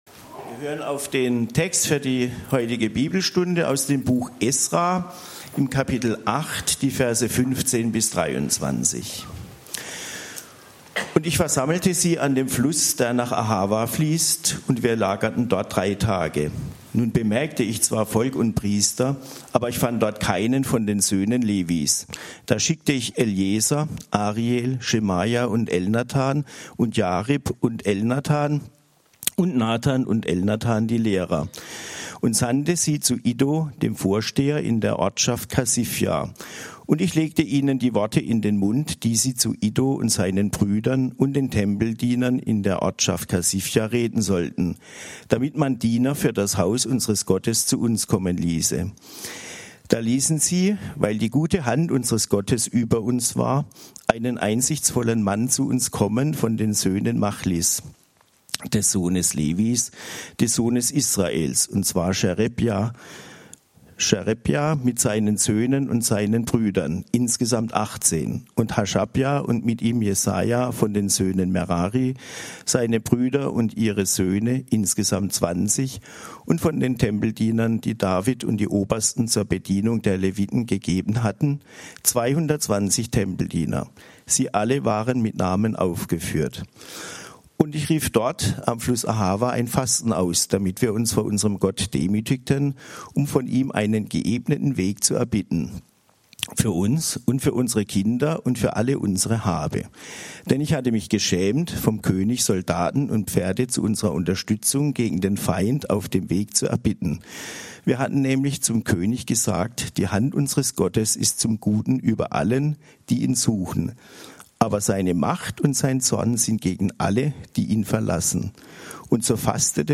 Bibelstunde ~ LaHö Gottesdienste Podcast